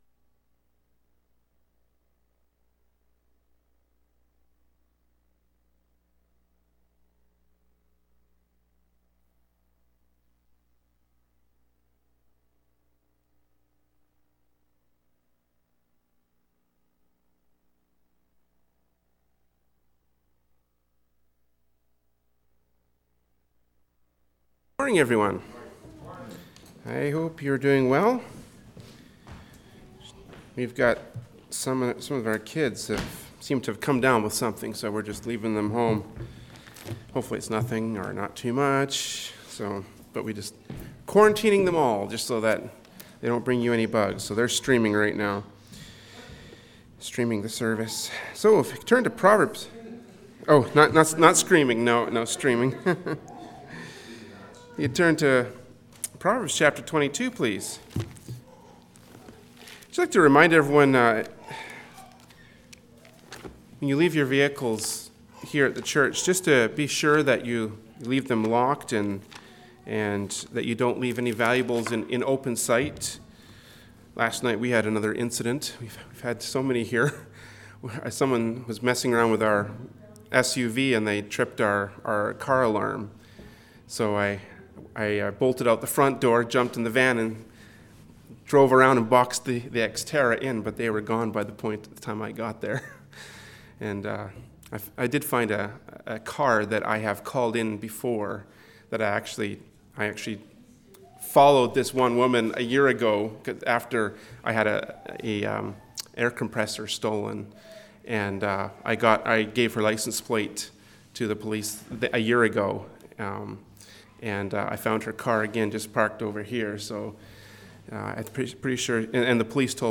“Proverbs 22:4” from Sunday School Service by Berean Baptist Church.
Passage: Proverbs 22:4 Service Type: Adult Sunday School “Proverbs 22:4” from Sunday School Service by Berean Baptist Church.